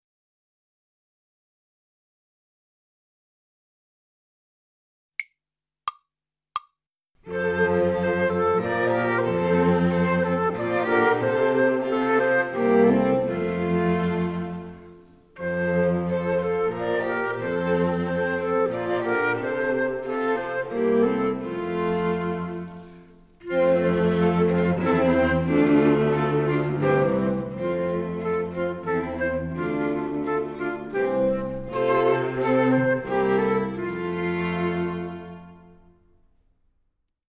Besetzung: Instrumentalnoten für Flöte